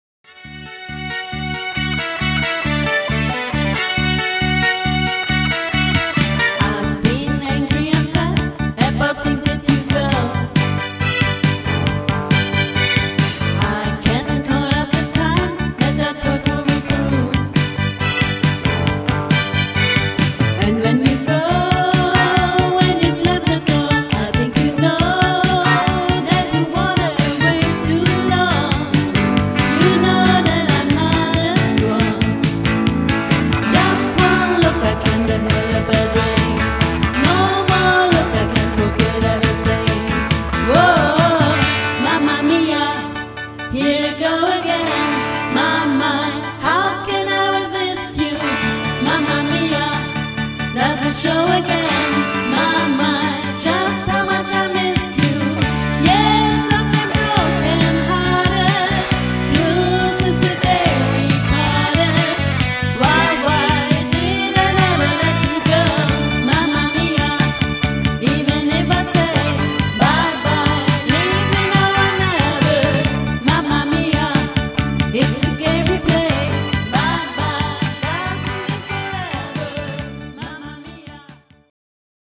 • Coverband
Liveaufnahme